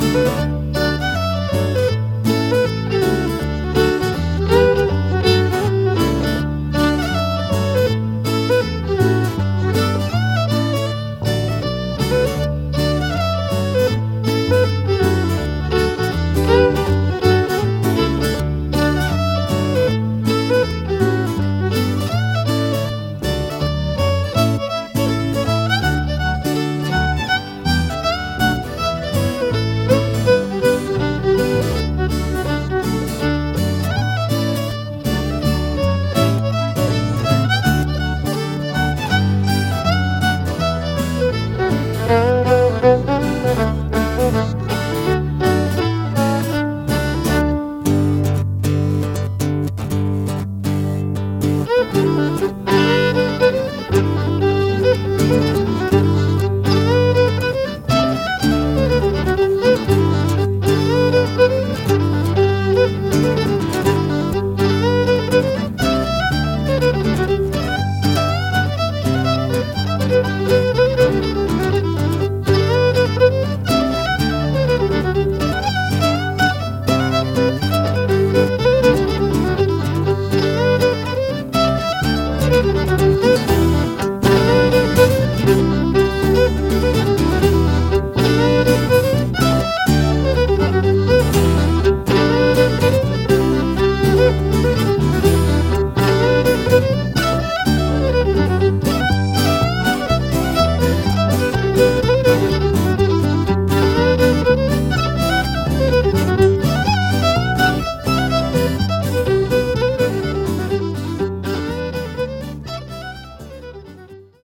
Hobsons Choice perform barn dances and ceilidhs throughout the year but most of our gigs are private parties and weddings.